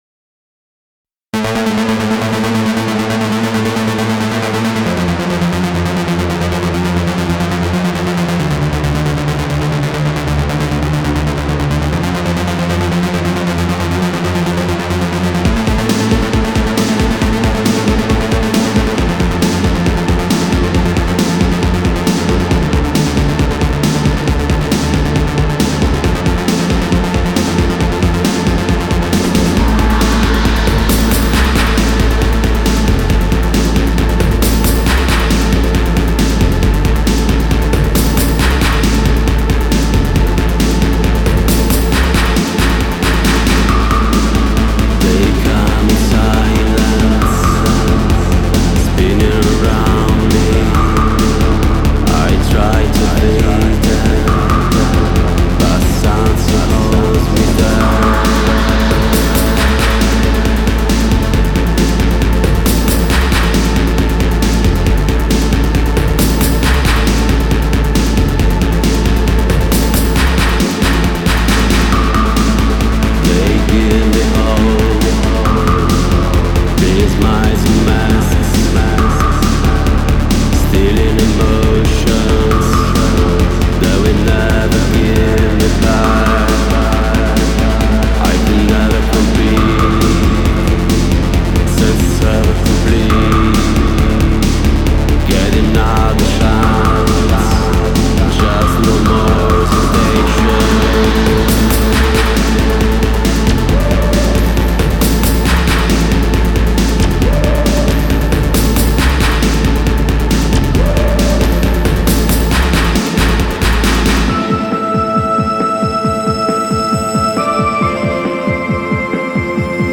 genre: Electro / Dark